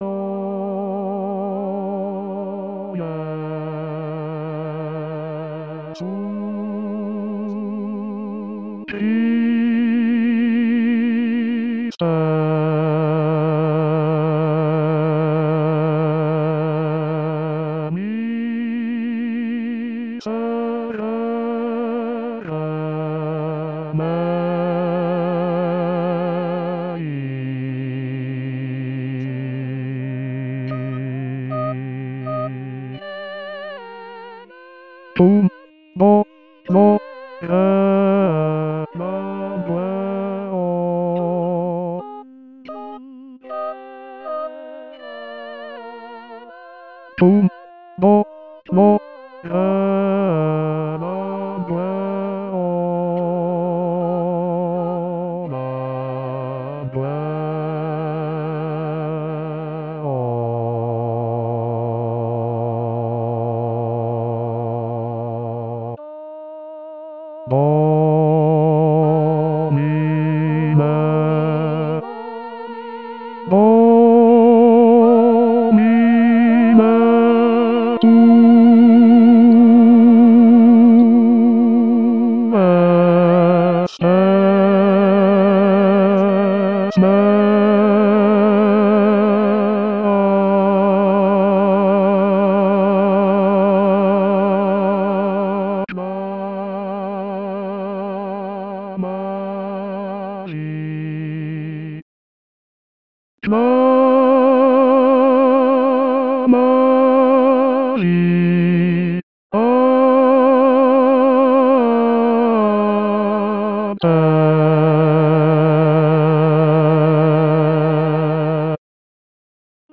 Musica SACRA Bajos
OJesuChriste_Ctda_Bajos.mp3